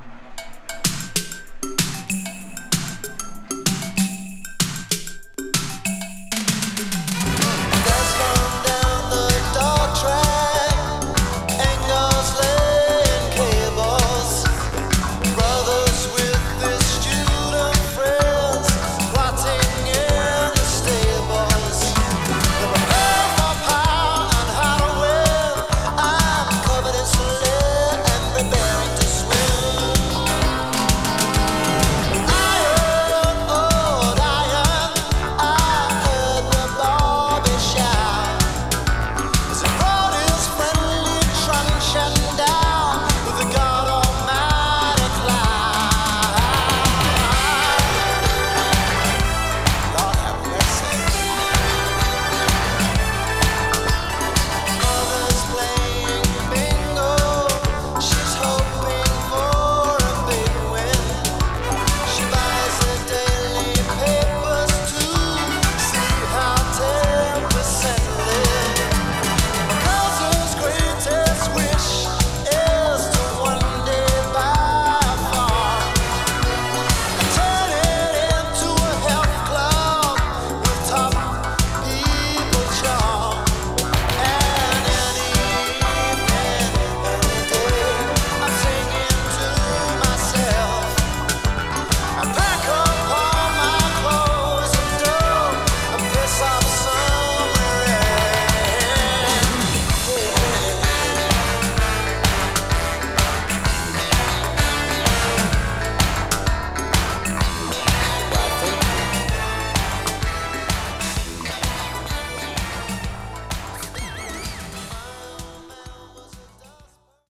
前半はピアノを中心としたアコースティック・サウンド、後半はブラコン系といった変則的な構成。